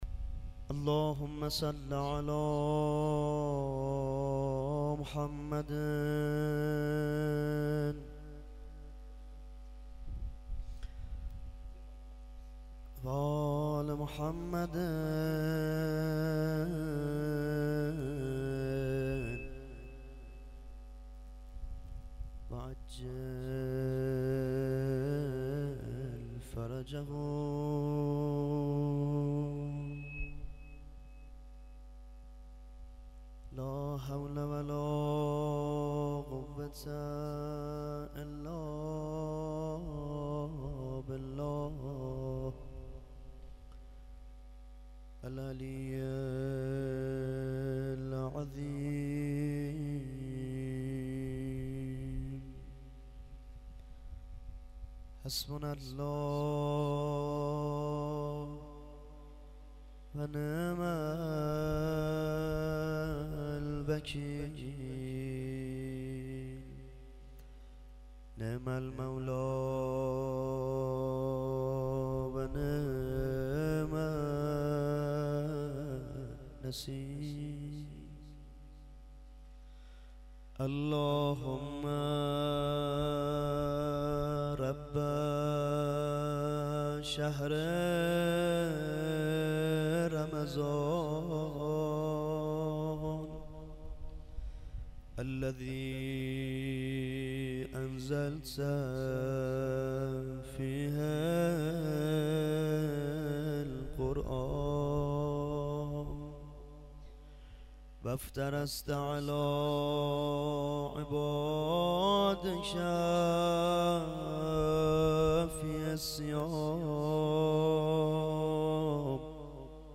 شب چهارم رمضان 1392 - حوزه علمیه نخبگان
monajat.mp3